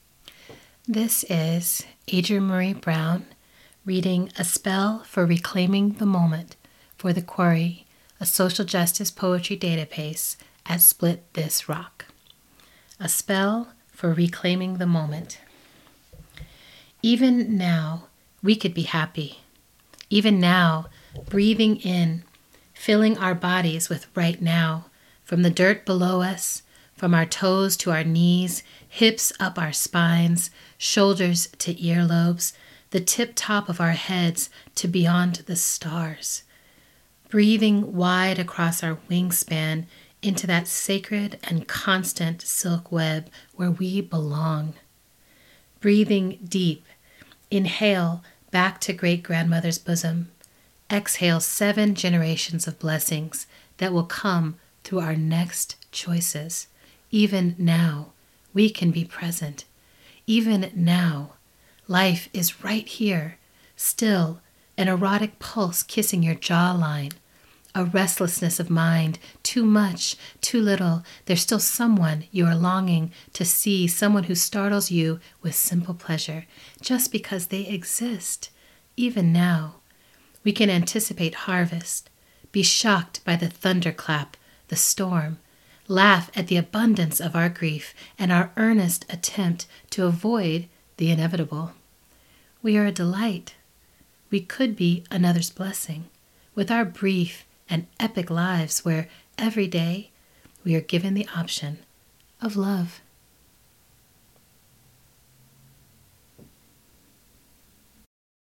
Listen as adrienne marie brown readsspell for reclaiming the moment.”